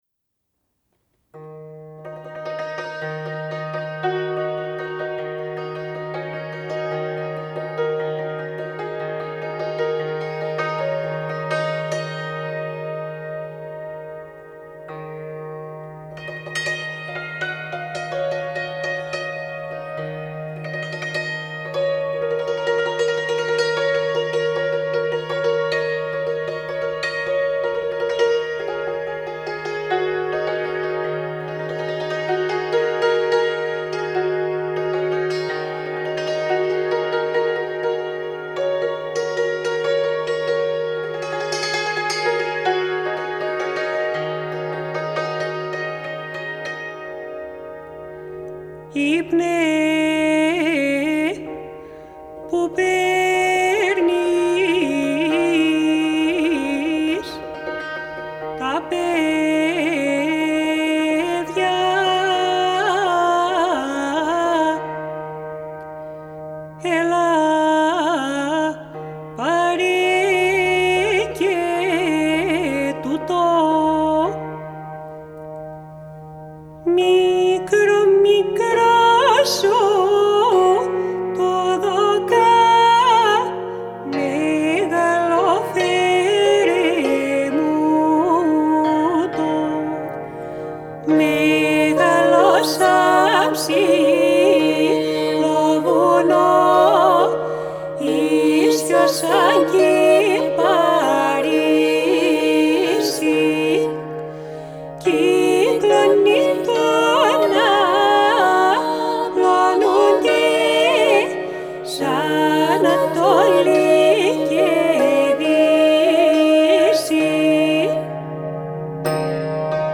Genre: World, Balkan Music